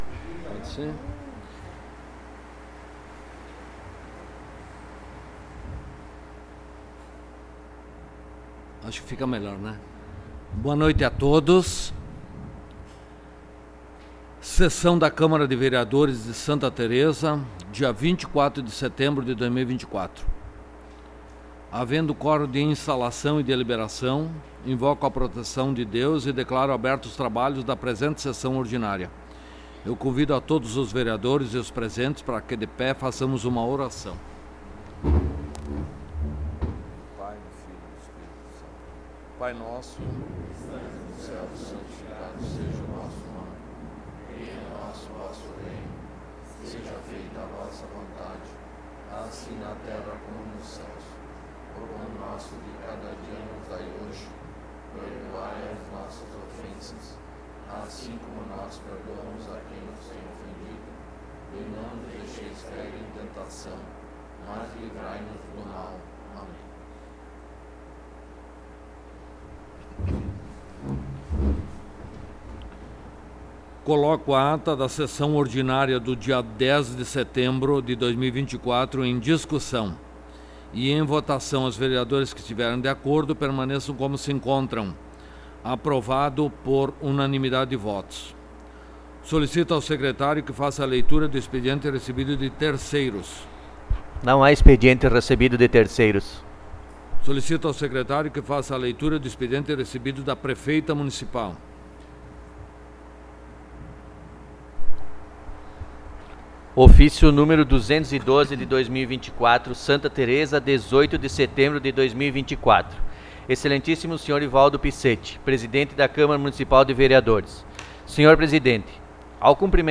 Local: Câmara Municipal de Vereadores de Santa Tereza
Áudio da Sessão